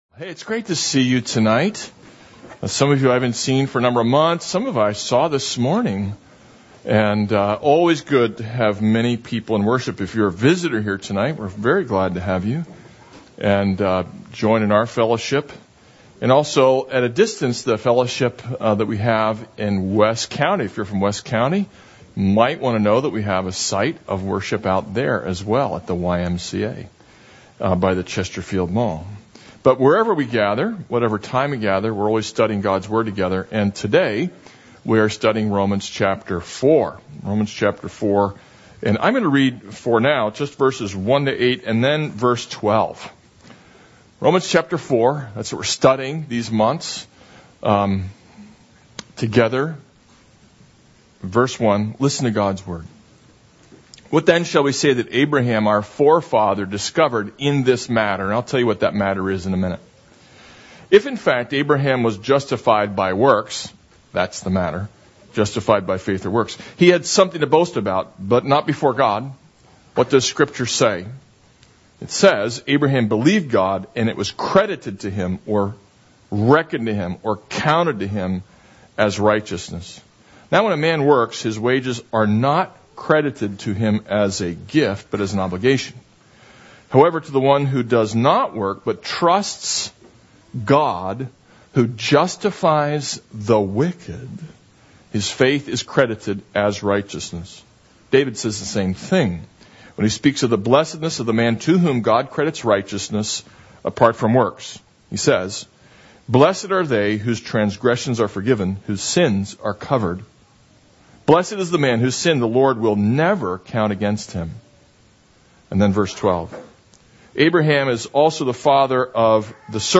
This is a sermon on Romans 4:1-12.